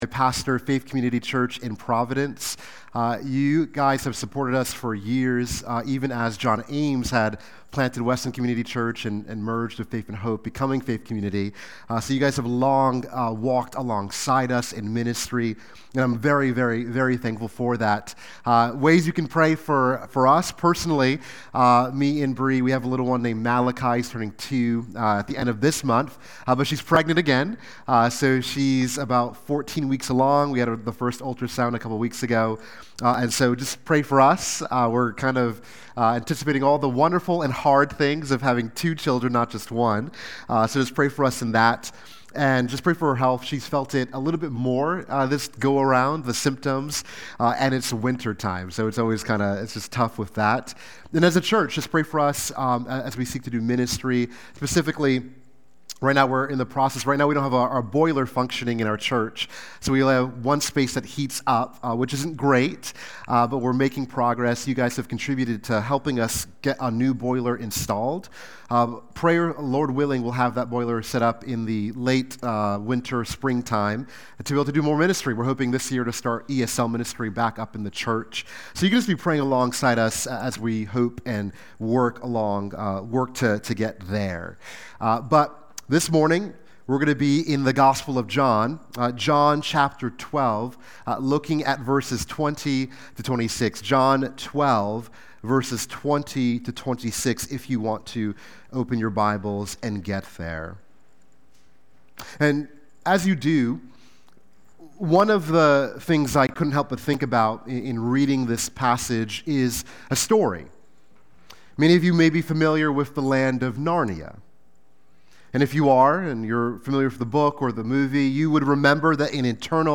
Sunday-Worship-main-21625.mp3